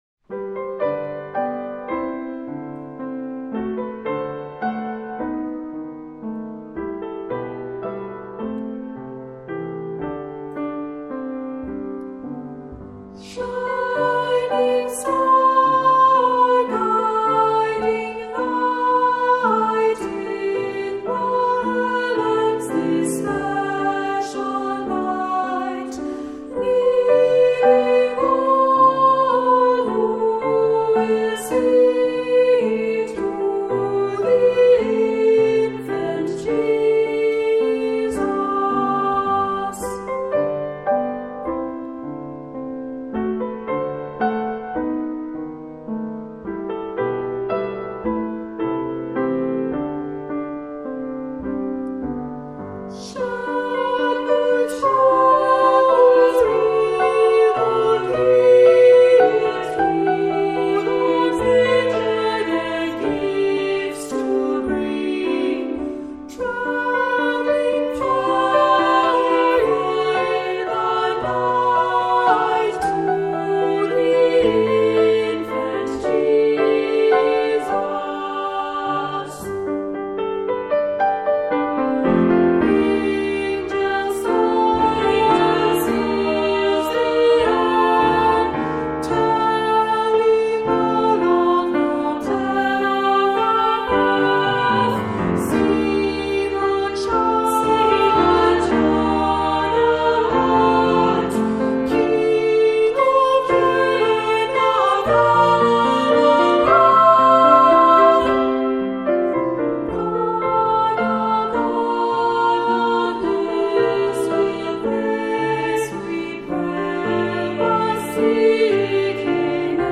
Voicing: 2-part Children's Choir - SA